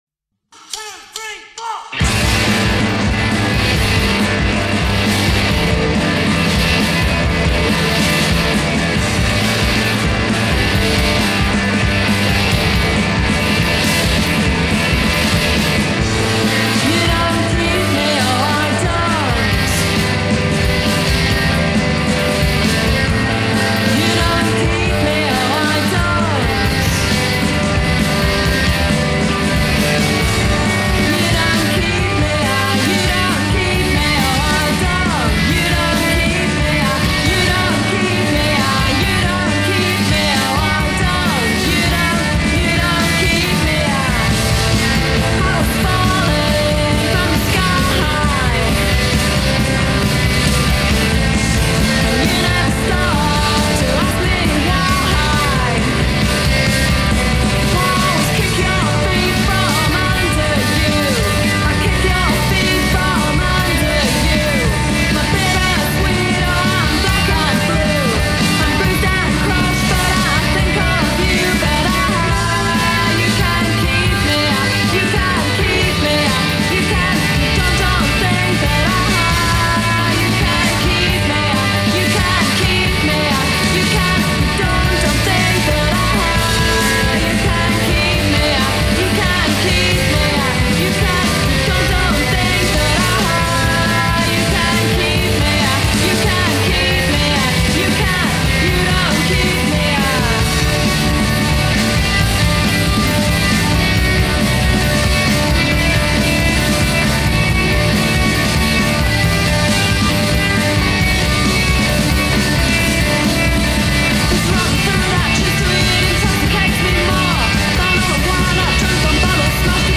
Indie, but more closely linked to Shoegaze